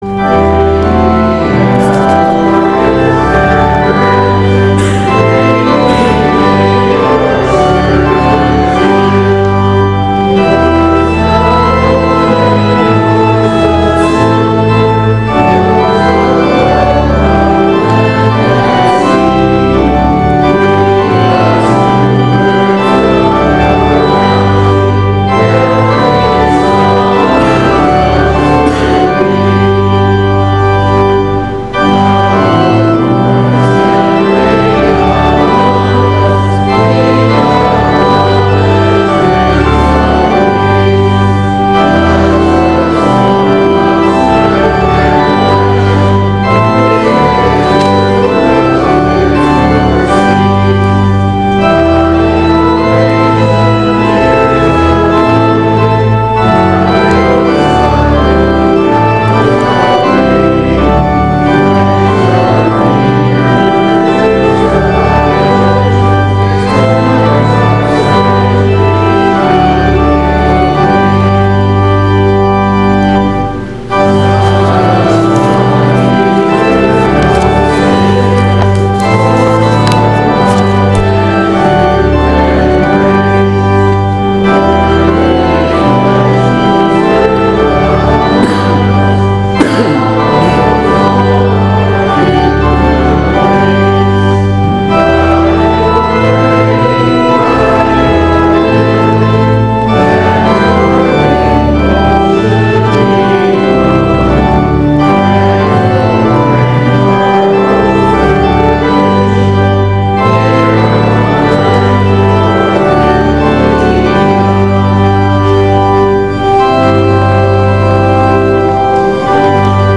Sermon for Pentecost 15 – September 13, 2020